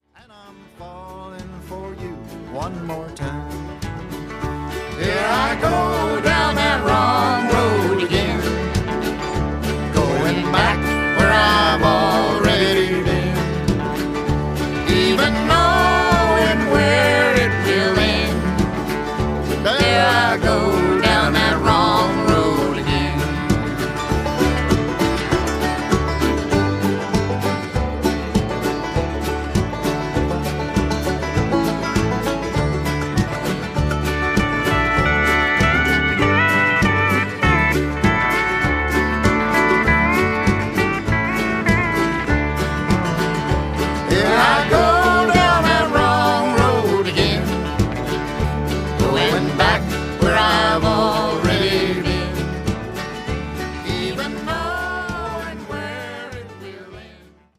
pedal steel
drums
fiddle, acoustic and electric guitars, vocals
banjo
harmonica
mandolin
piano